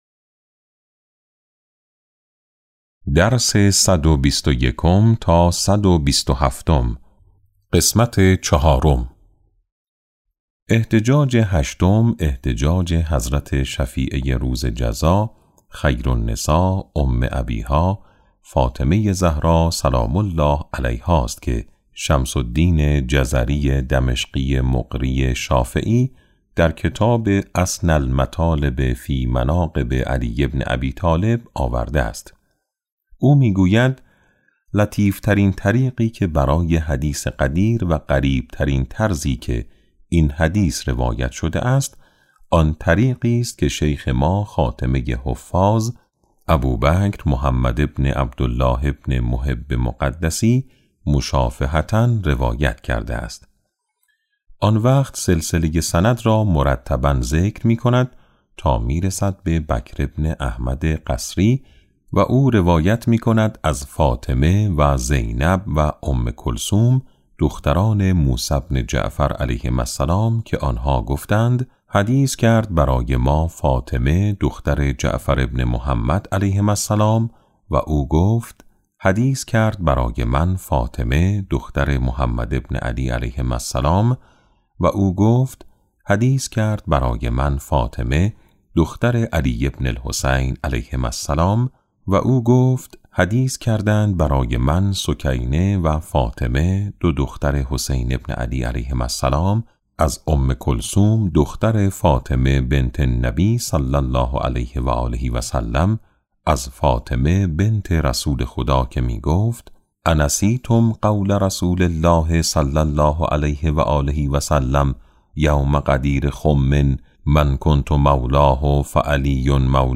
کتاب صوتی امام شناسی ج9 - جلسه4